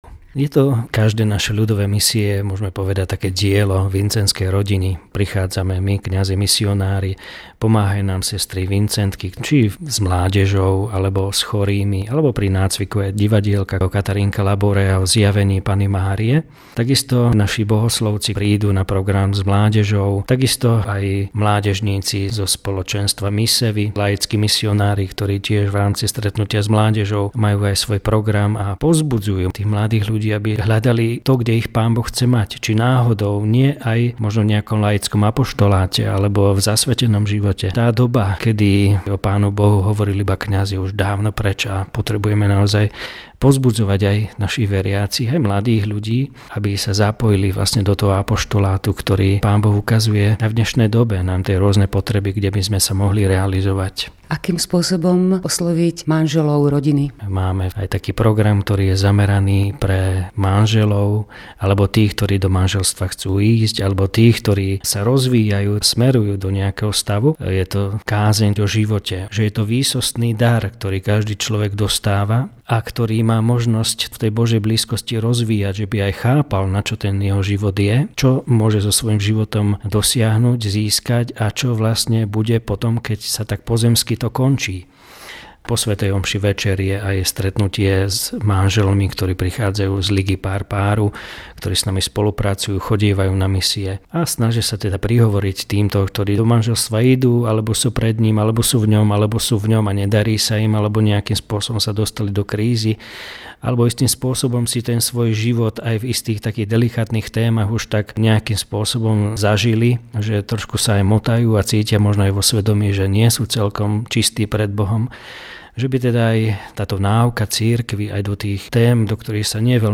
Reportáž z rádia Lumen (mp3, 6MB)